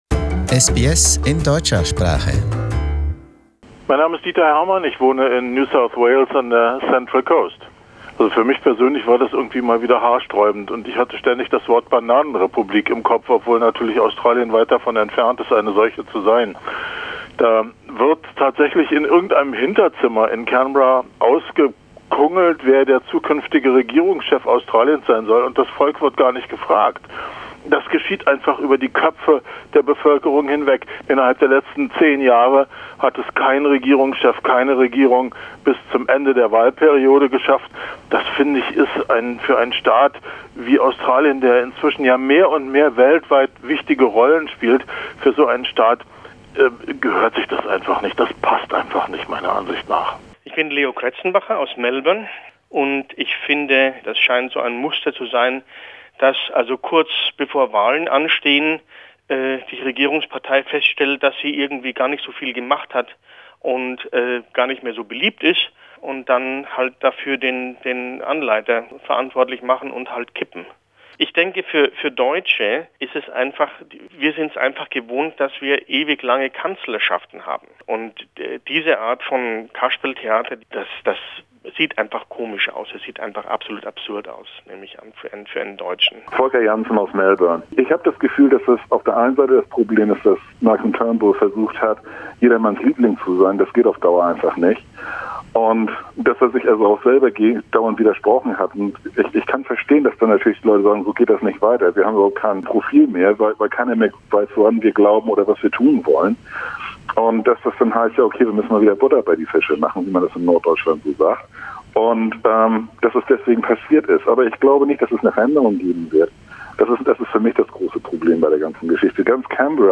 Wir haben in der deutschen Community nachgefragt, wie der unerwartete Wechsel an der Regierungsspitze und die Folgen für Australien einzuschätzen sind.